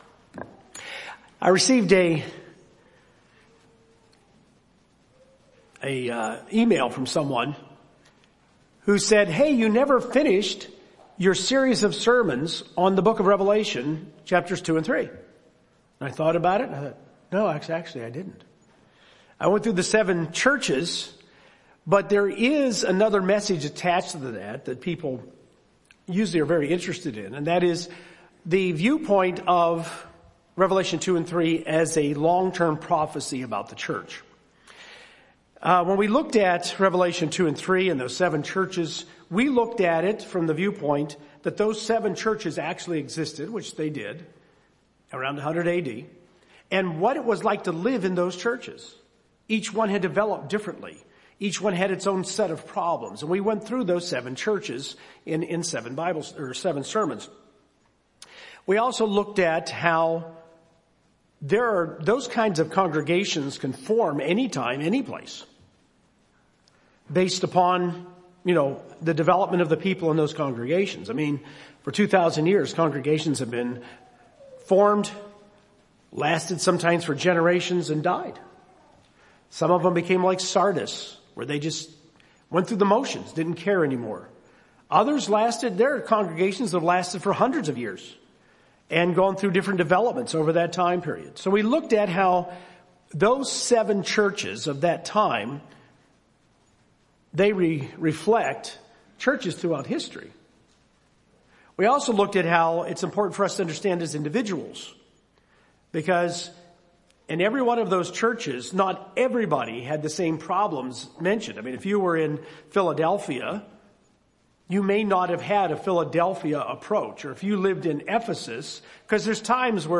A brief overview of the prophetic meaning of the letters to the churches in Revelation 2 and 3, with additional info from the book of Colossians to understand what the issues in Laodicea were. Final sermon in the eight-part series.